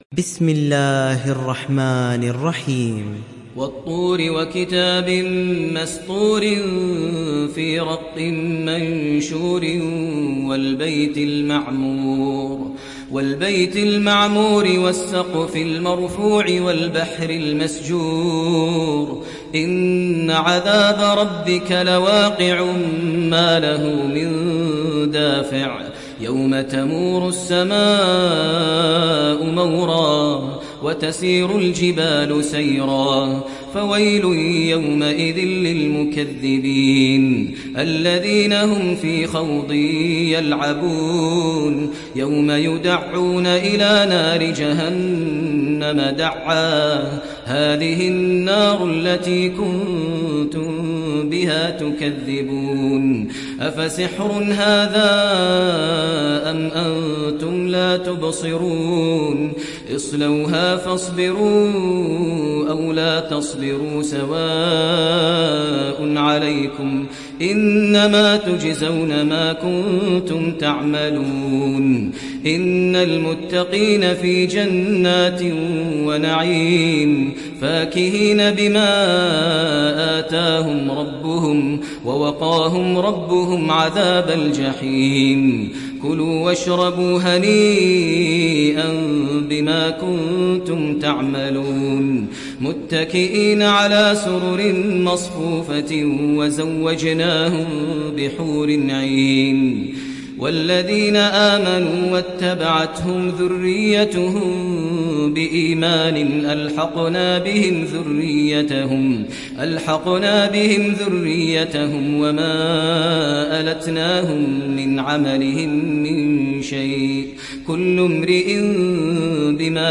সূরা আত-তূর ডাউনলোড mp3 Maher Al Muaiqly উপন্যাস Hafs থেকে Asim, ডাউনলোড করুন এবং কুরআন শুনুন mp3 সম্পূর্ণ সরাসরি লিঙ্ক